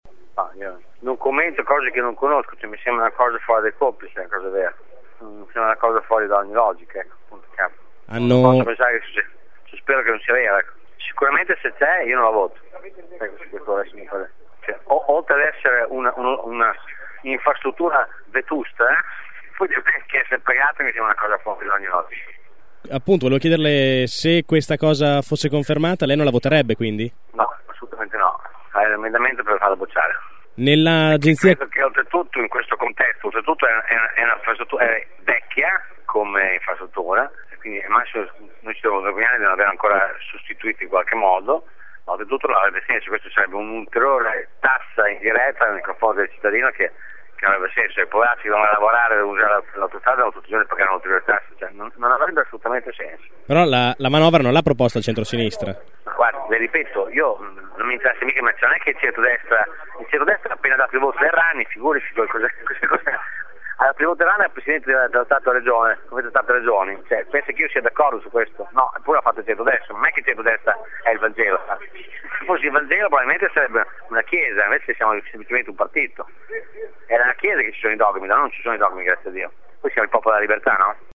Il deputato del Pdl Enzo Raisi non conosce il testo ma ai nostri microfoni spiega che “spera non sia vero, se lo fosse non lo voterò”.